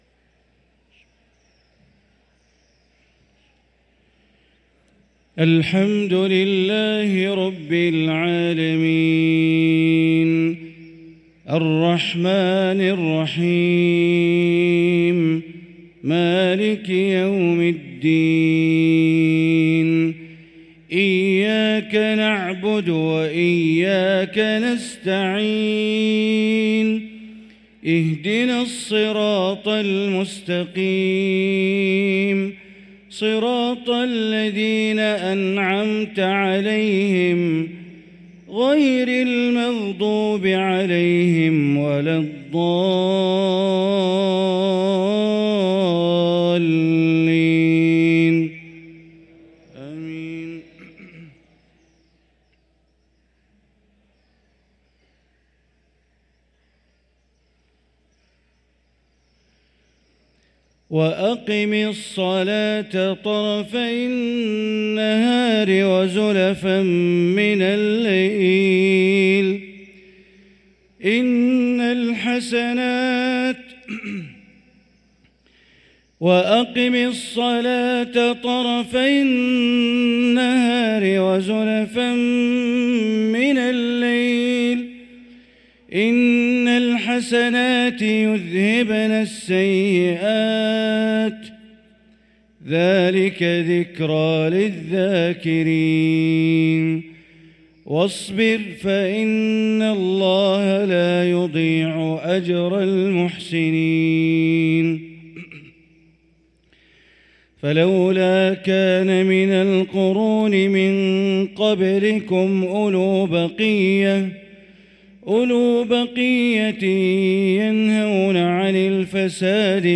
صلاة المغرب للقارئ بندر بليلة 15 رجب 1445 هـ